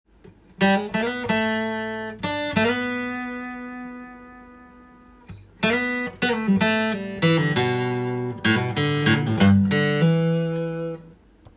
この曲のリードギターのメロディーは意外と細かいのが特徴です。